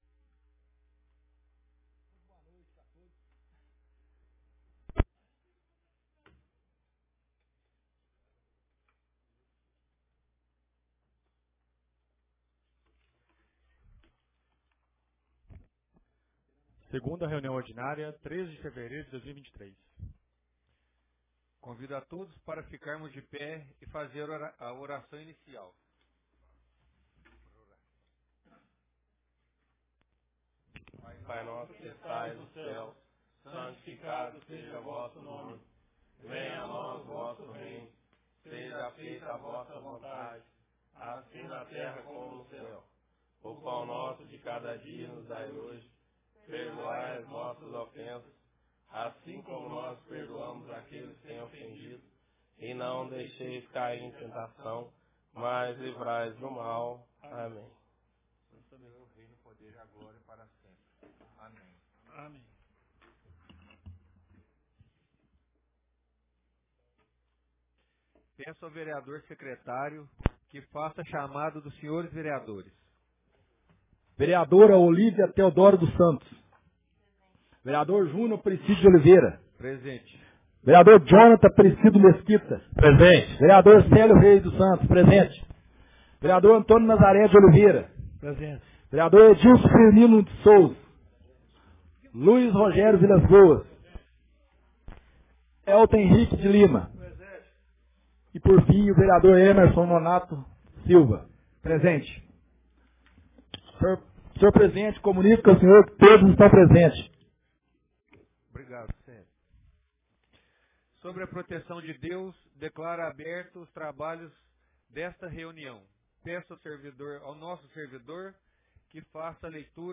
Ata da 2ª Reunião Ordinária de 2023 — Câmara Municipal